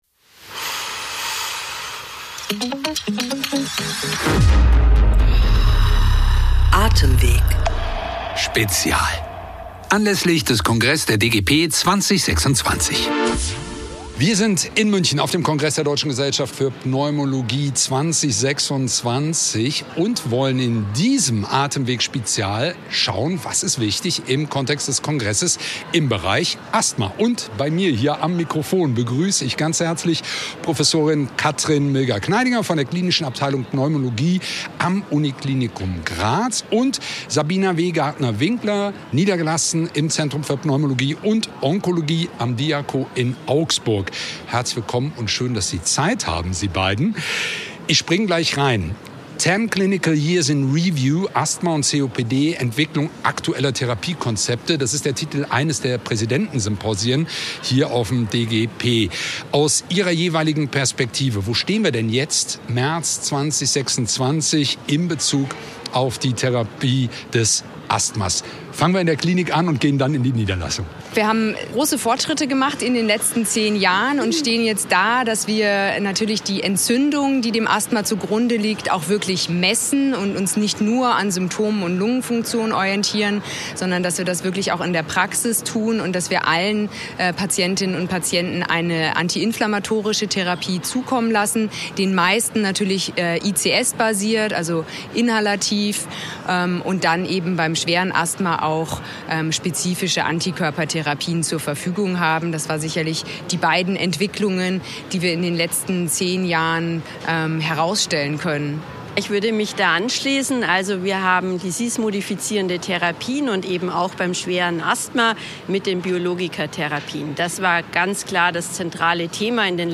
Wir berichten live vom 66. DGP-Kongress in München: In unserer dreiteiligen Podcastreihe sprechen wir mit renommierten Expert*innen über COPD, Asthma, sowie Idiopathische Lungenfibrose und Interstitielle Lungenerkrankungen.